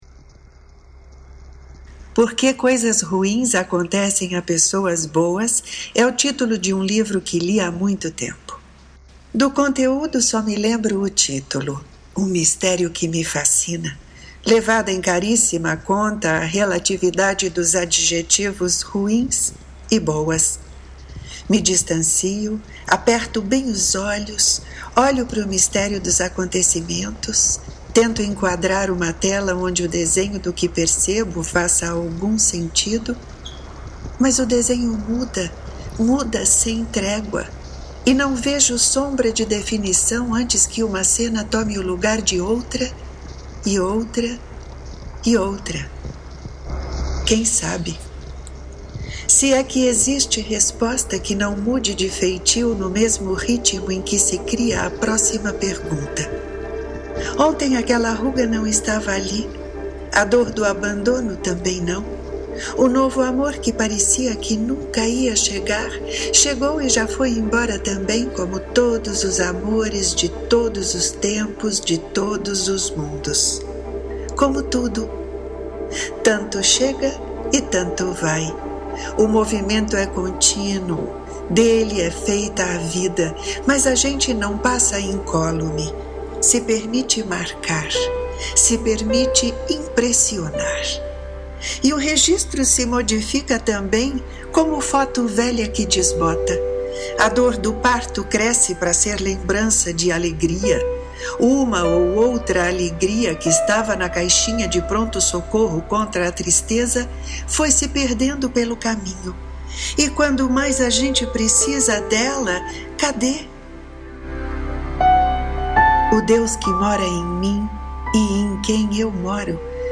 Ouça De crime e castigo na voz e sonorizado pela autora